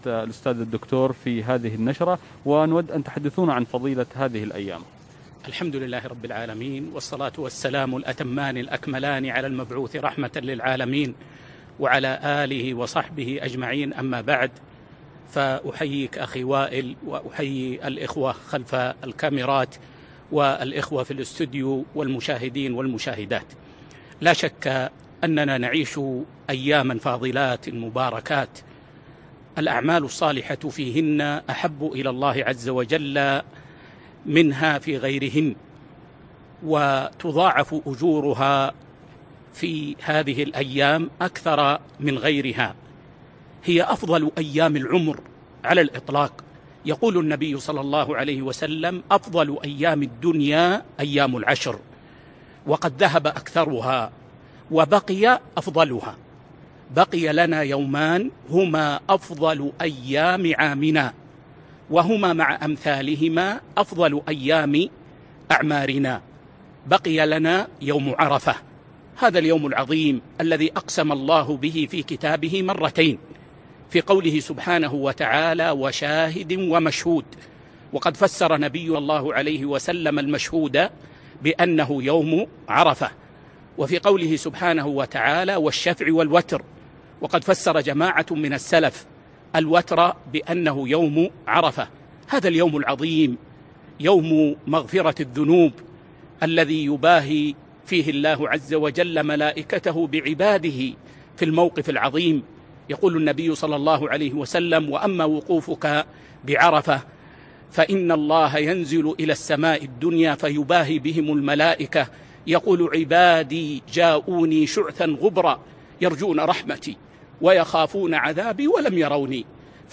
فضل يوم عرفة - لقاء تلفزيوني 8 ذو الحجة 1441 هــ